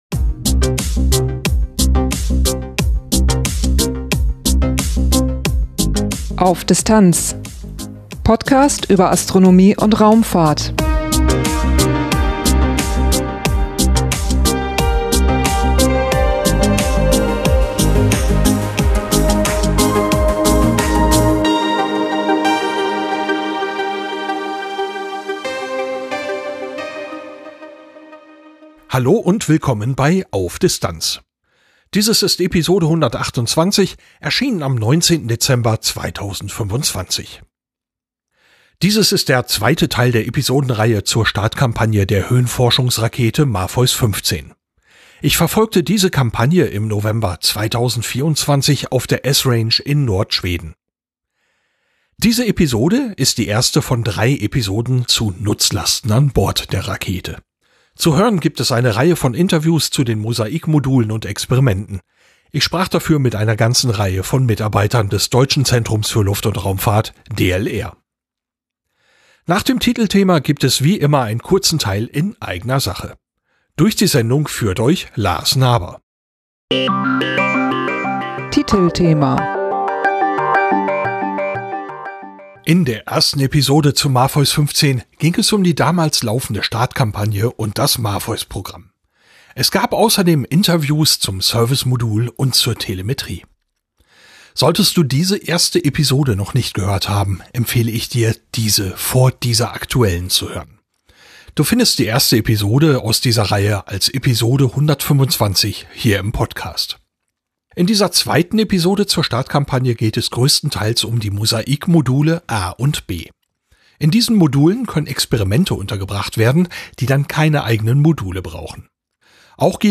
Dieses ist der zweite Teil der Episodenreihe zur Startkampagne der Höhenforschungsrakete MAPHEUS-15. Ich verfolgte diese Kampagne im November 2024 auf der Esrange in Nord-Schweden.
Zu hören gibt es eine Reihe von Interviews zu den MOSAIC-Modulen und Experimenten.